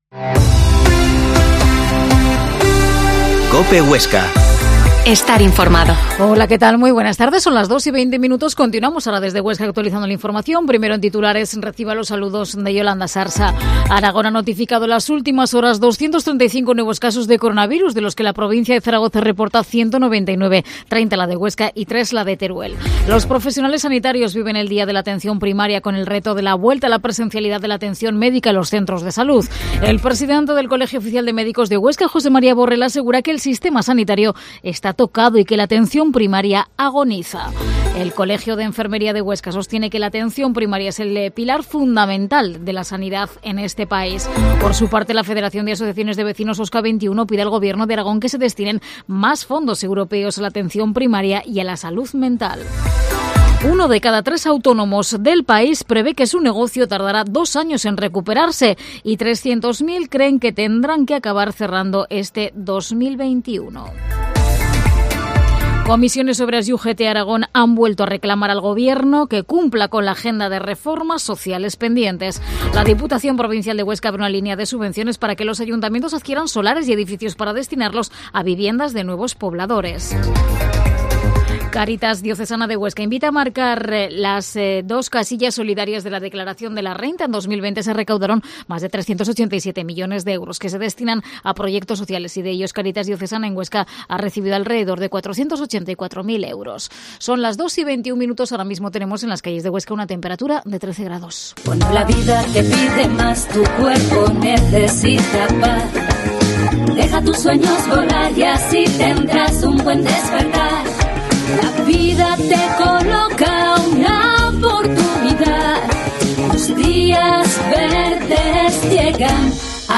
Informativo Mediodía en Huesca